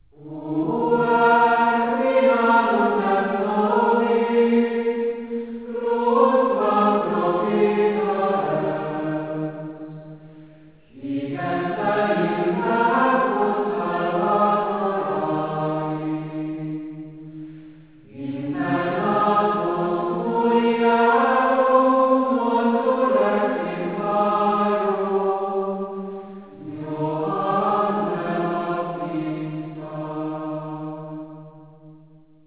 bevezető gregorián énekekkel